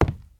PixelPerfectionCE/assets/minecraft/sounds/step/wood2.ogg at mc116
wood2.ogg